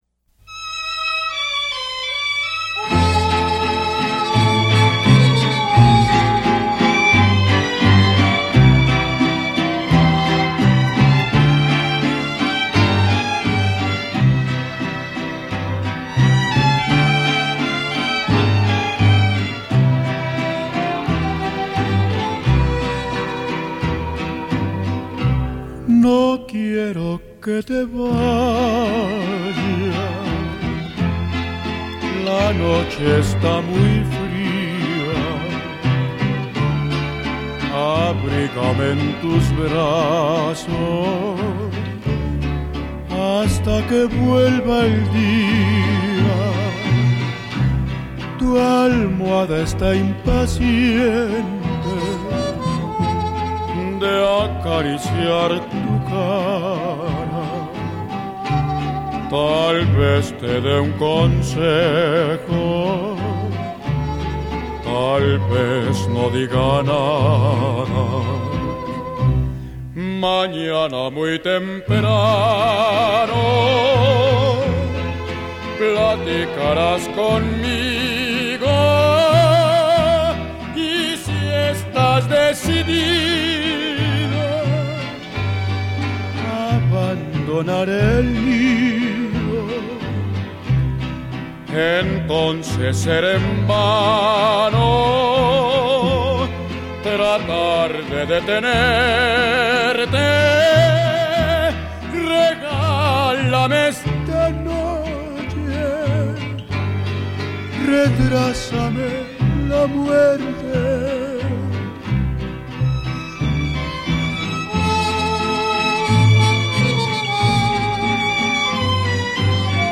bolero ranchero